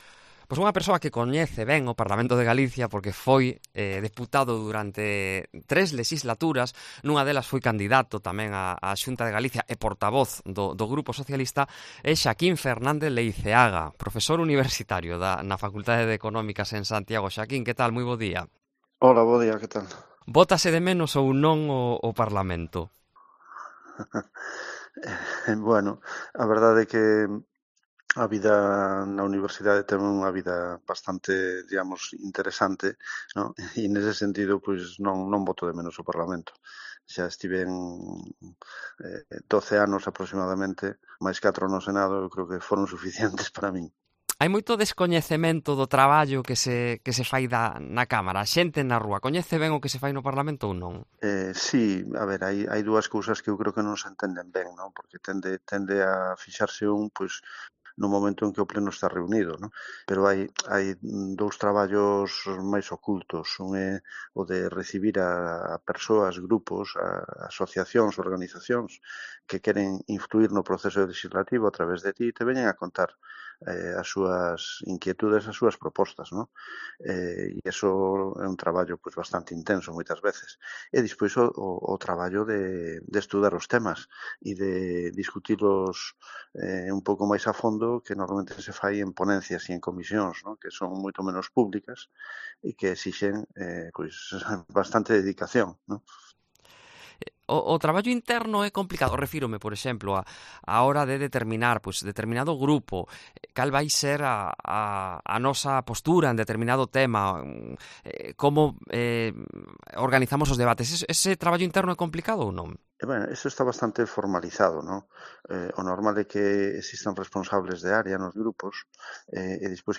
Lo mejor de ser diputado autonómico es poder influir en la política de Galicia, algo que se puede hacer incluso estando en la oposición. Es la reflexión en Herrera en Cope Galicia de Xoaquín Fernández Leiceaga, profesor de Economía en la Universidade de Santiago de Compostela (USC) que formó parte del Parlamento de Galicia durante tres legislaturas.